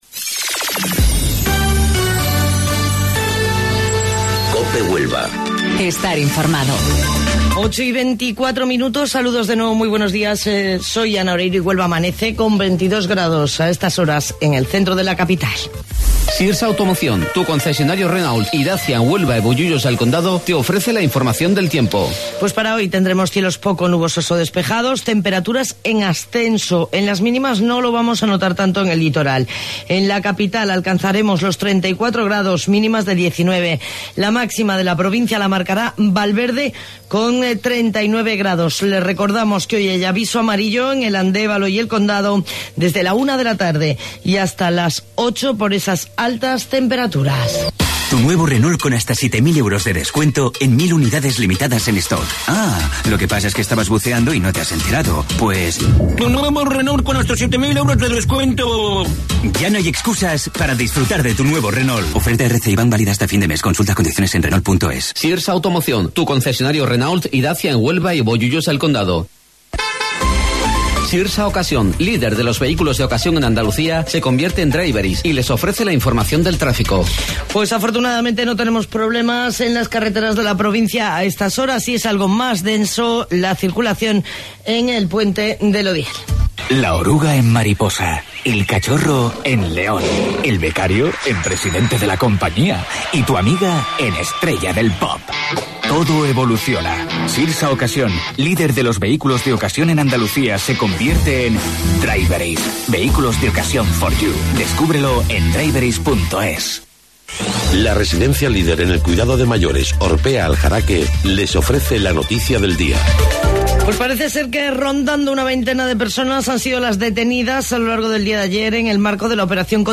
AUDIO: Informativo Local 08:25 del 19 de Julio